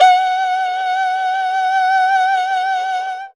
52-bi11-erhu-f-f#4.wav